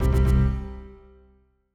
Longhorn XP - Critical Stop.wav